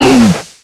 Cri de Chartor dans Pokémon X et Y.